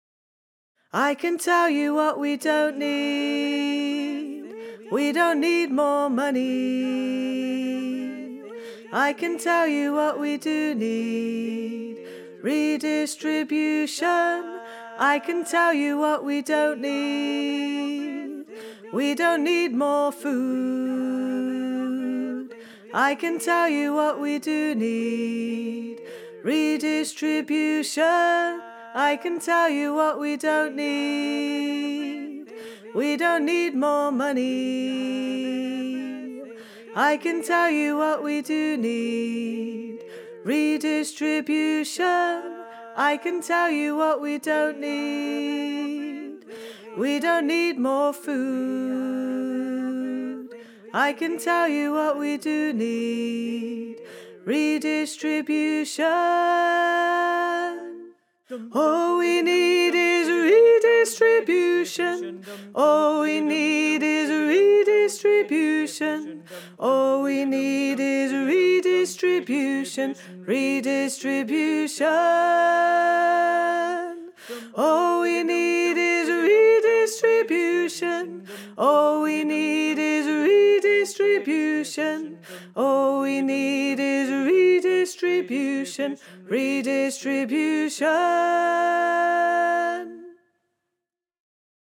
Alto:
redistribution_learning-tracks_alto.wav